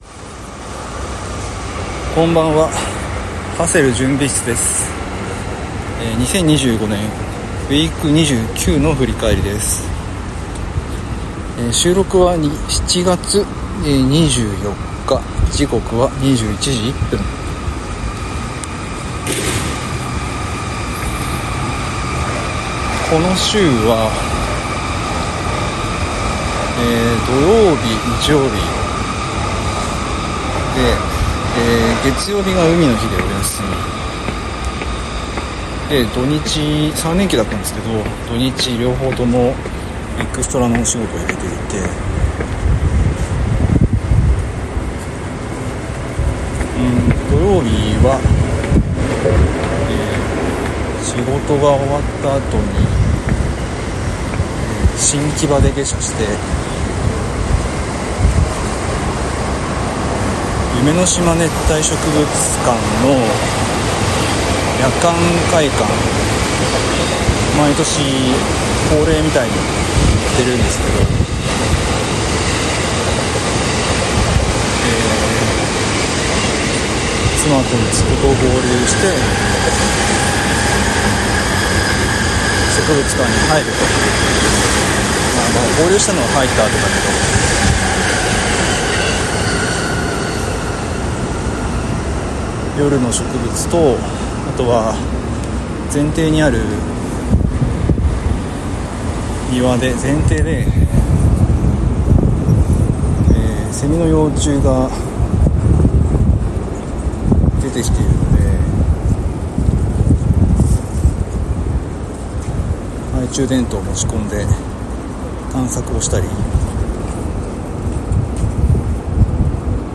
Audio Channels: 1 (mono)